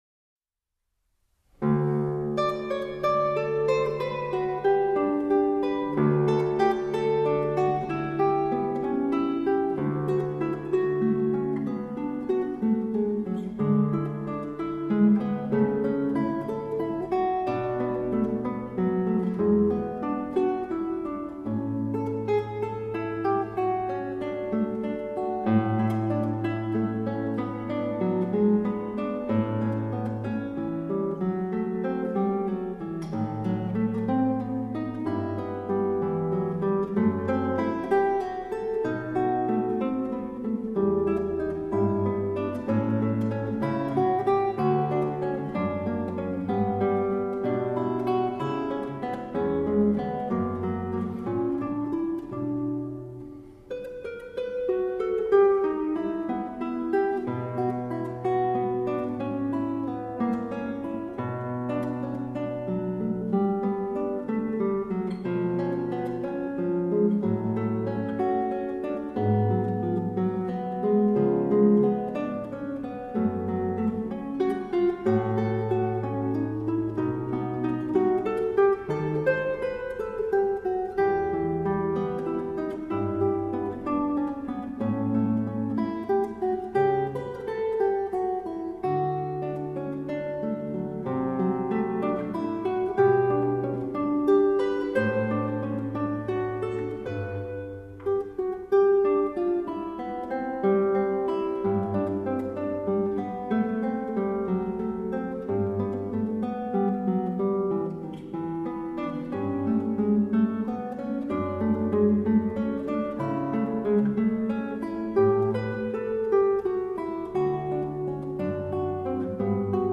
Romanische Basilika auf dem Petersberg